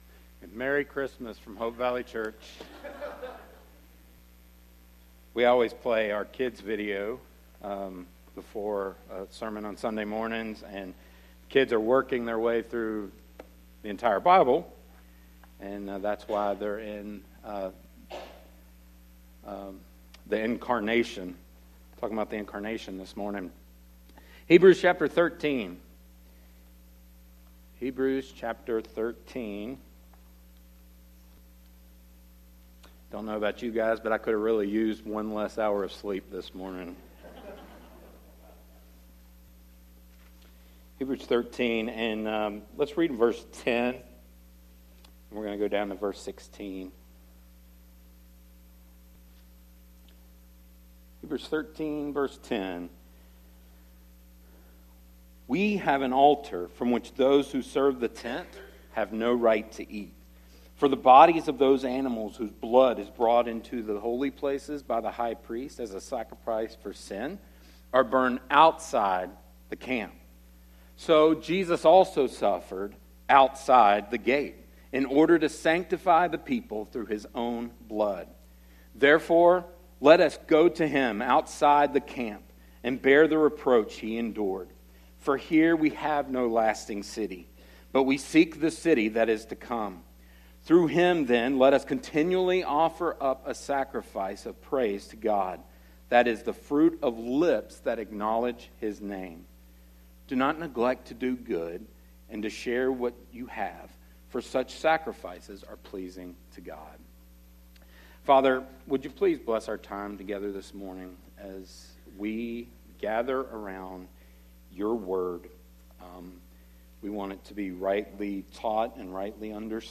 sermon-audio-trimmed-1.mp3